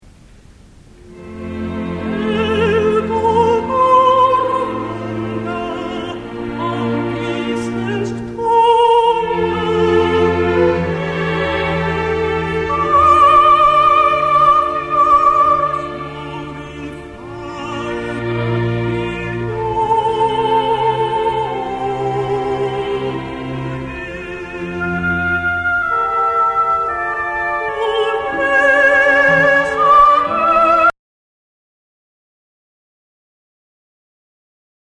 ath í tóndæminu er mezzo sópran sem syngur
Sóprano sóló